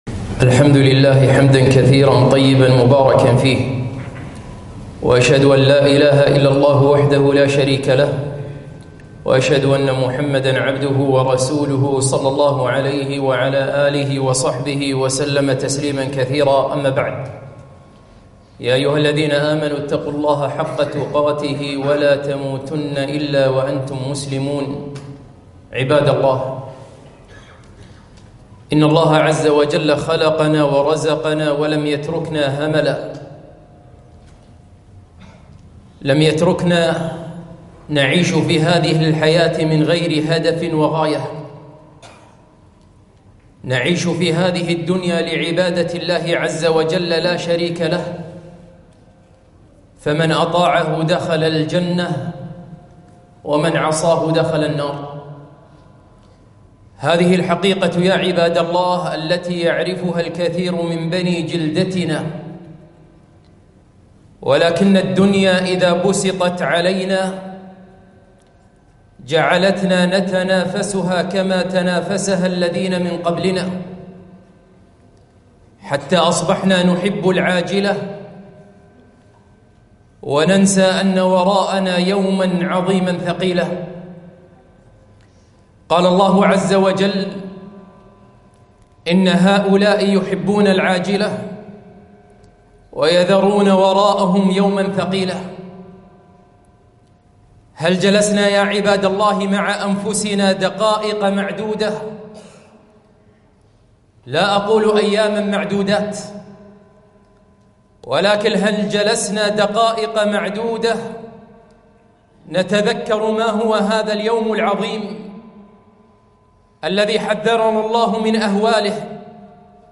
خطبة - تـذكروا هذا اليوم